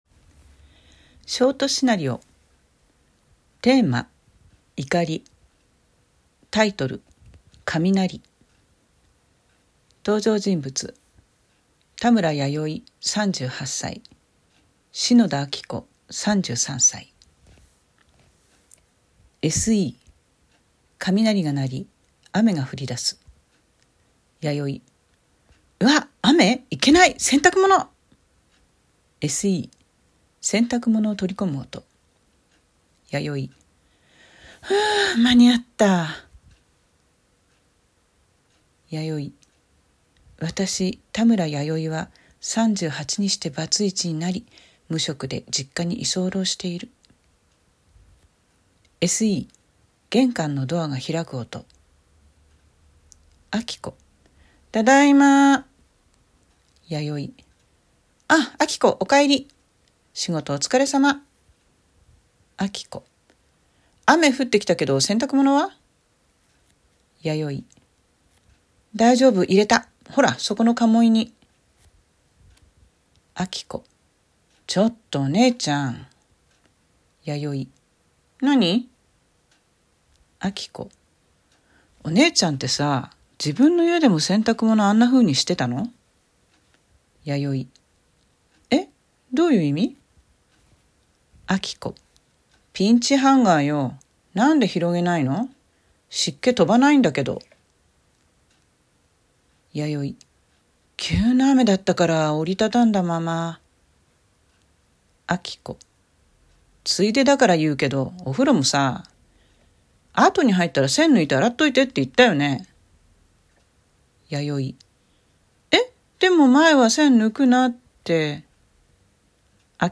習作朗読『カミナリ』
ショートシナリオ